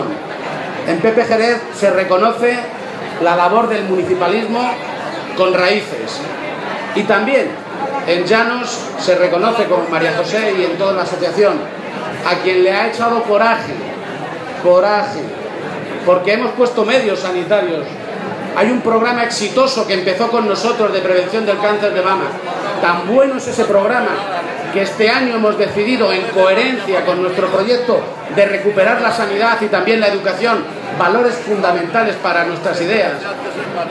García-Page en la entrega de los Premios "Pablo Iglesias" entregados en la caseta de la Casa del Pueblo, en el Recinto Ferial de Albacete
Cortes de audio de la rueda de prensa